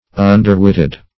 Search Result for " underwitted" : The Collaborative International Dictionary of English v.0.48: Underwitted \Un"der*wit`ted\, a. Weak in intellect; half-witted; silly.